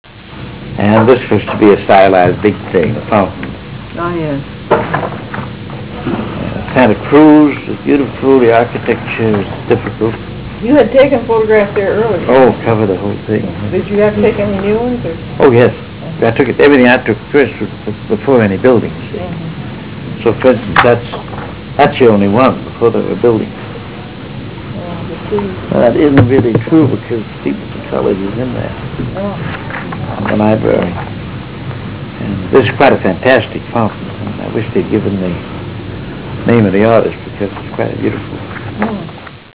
317Kb Ulaw Soundfile Hear Ansel Adams discuss this photo: [317Kb Ulaw Soundfile]